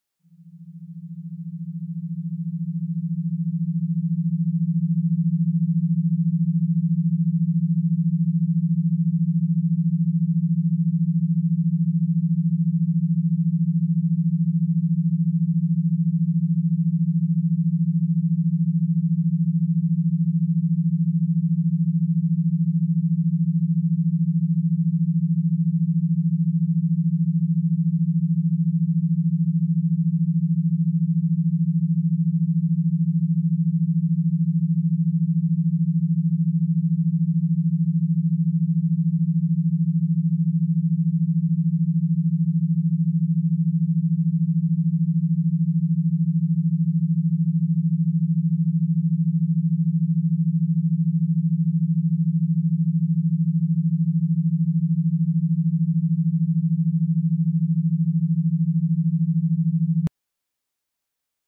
Binaural Beats 160hz sound effects free download